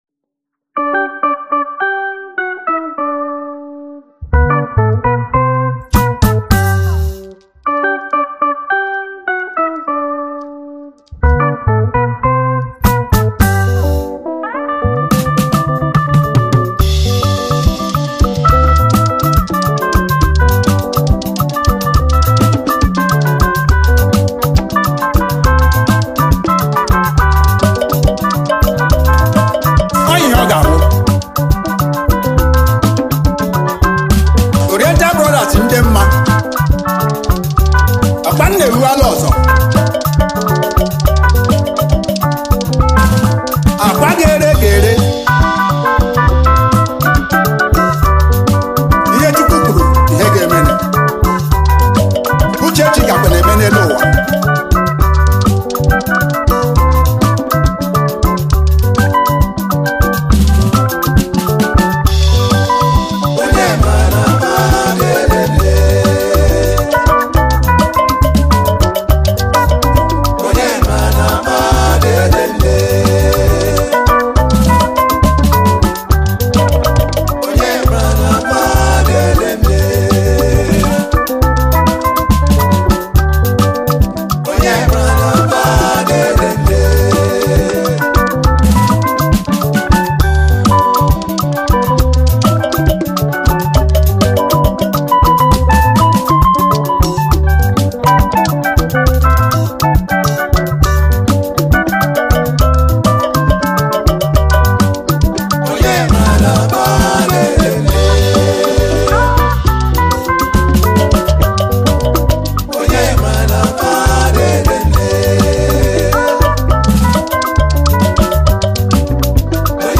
Gospel
was a Nigerian orchestra high life band from Eastern Nigeria